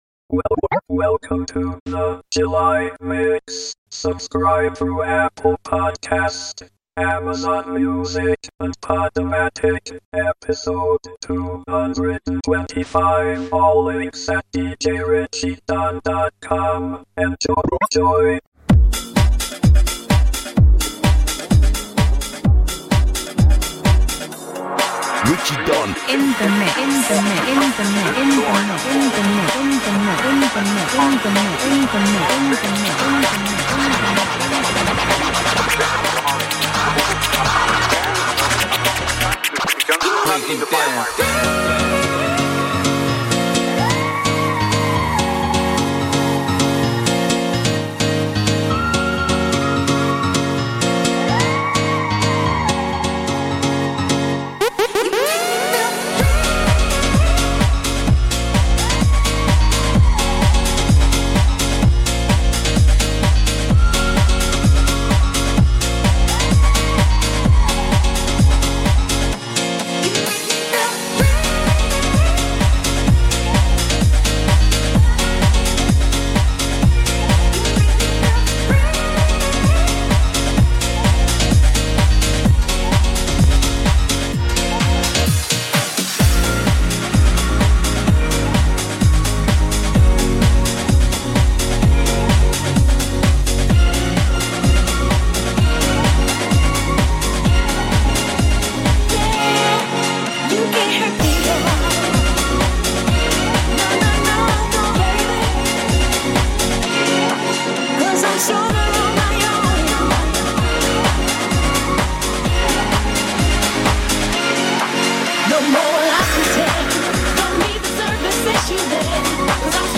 Mash Ups / Remixes / Future Anthems
Bassline / Garage / Exclusives